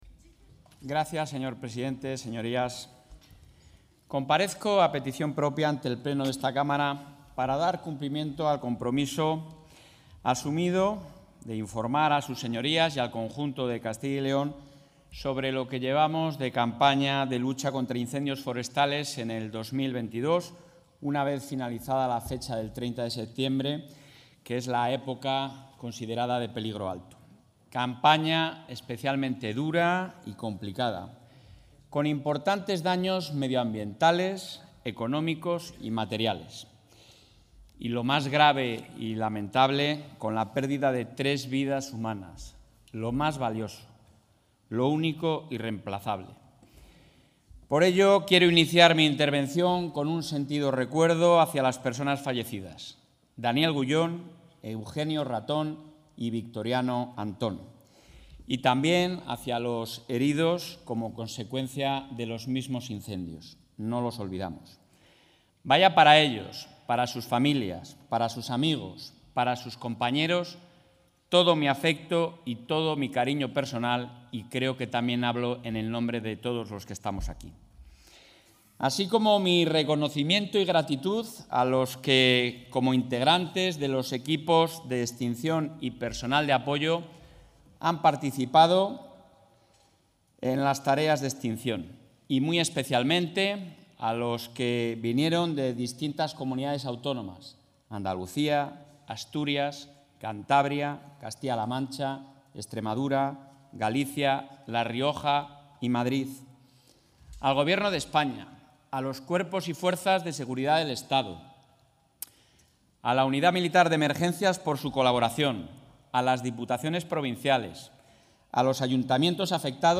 El presidente de la Junta de Castilla y León, Alfonso Fernández Mañueco, comparece hoy a petición propia ante el pleno de las Cortes de Castilla y León para informar sobre la campaña contra incendios forestales 2022.